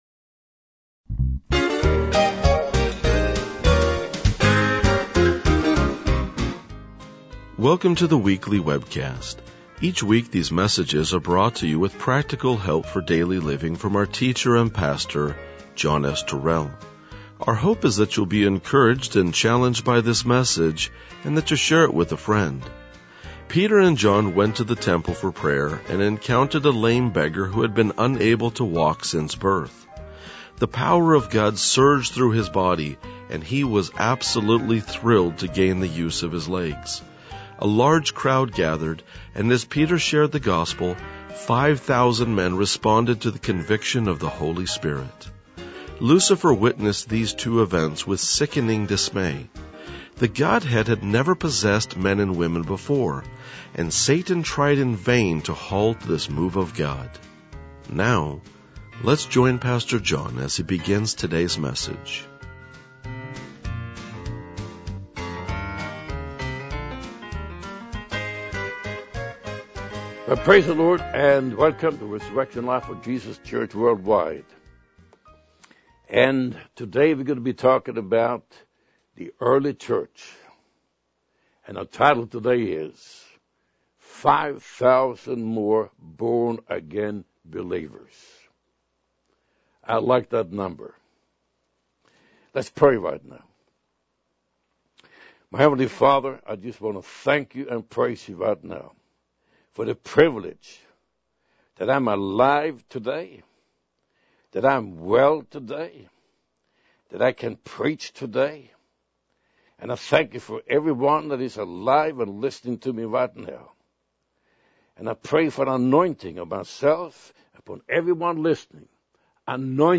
RLJ-2000-Sermon.mp3